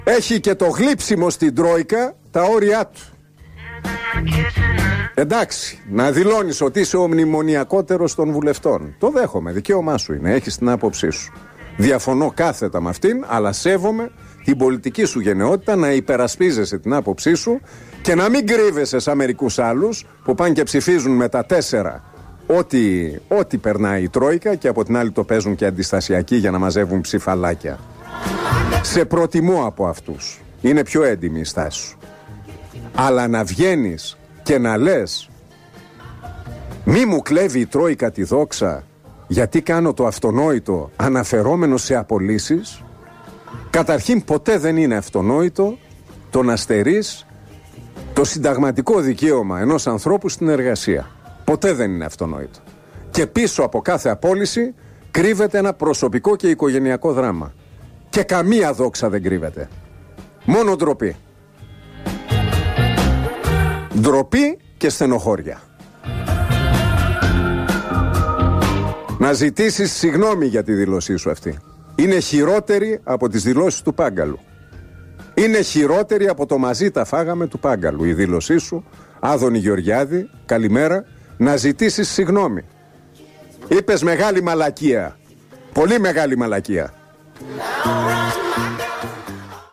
Η χθεσινή δήλωση του υπουργού υγείας, Άδωνι Γεωργιάδη, που ζήτησε να μην του πάρουν τη δόξα των απολύσεων οι τροϊκανοί εκνεύρισε τον Νίκο Χατζηνικολάου, που μέσω της εκπομπής του στο ραδιόφωνο σήμερα το πρωί απευθυνόμενος στον υπουργό υγείας του είπε σε έντονο ύφος πως «λέει μ...κίες» και πως «πρέπει να ζητήσει συγγνώμη».
Ο δημοσιογράφος συνέχισε να κατακρίνει τον πολιτικό μέσα από την εκπομπή του στον Realfm, αναφέροντας πως είναι «ντ ροπή η λέξη δόξα για απολύσεις» και πως θα «πρέπει να μη σε πιάνει ύπνος όταν μιλάς για απολύσεις και όχι να θεωρείς ότι δοξάζεσαι και να ζητάς συγγνώμη»